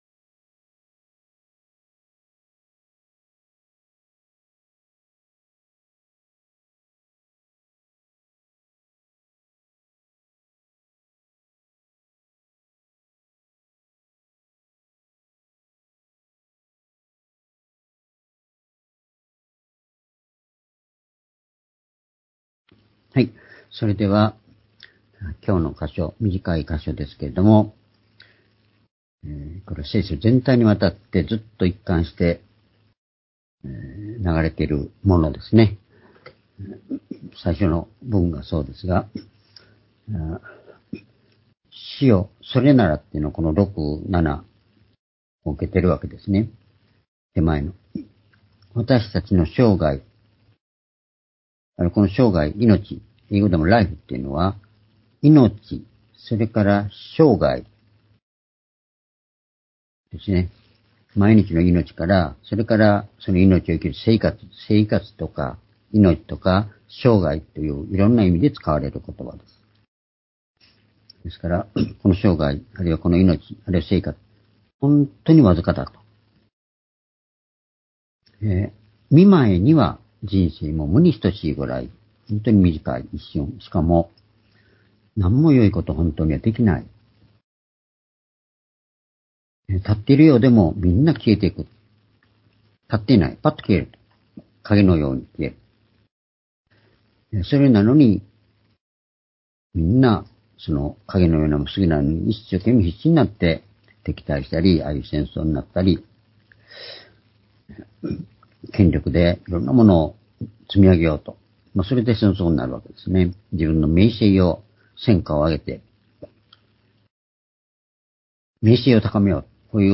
（主日・夕拝）礼拝日時 ２０２３年12月19日（夕拝) 聖書講話箇所 「主よ、それなら何に望みをかけたらよいでしょう」 詩編３９の８－１０ ※視聴できない場合は をクリックしてください。